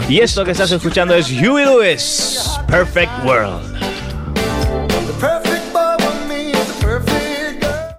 Presentació d'un tema musical.
Entreteniment